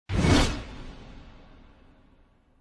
Download Zoom sound effect for free.
Zoom